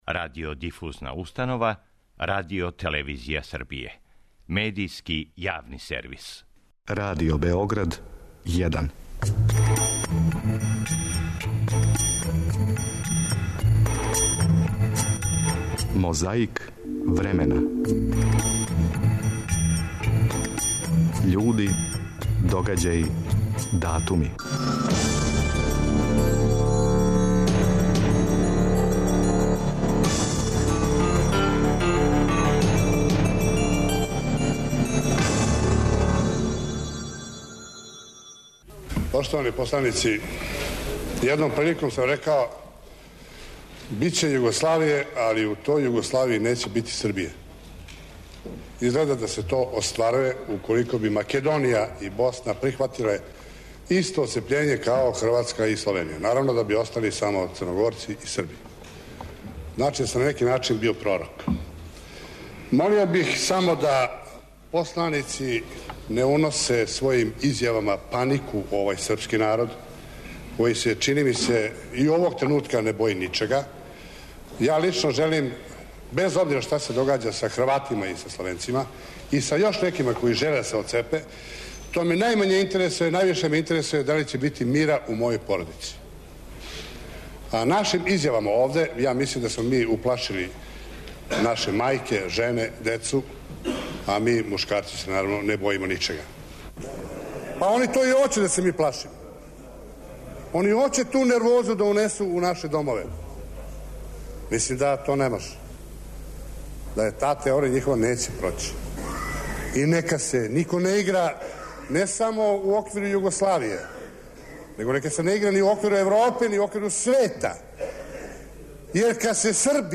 Чућете званично саопштење Берлинског радија о нападу Немачке на Совјетски Савез, 22.јуна 1941. године.